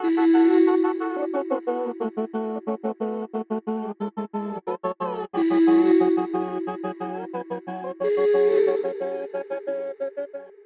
vox3-1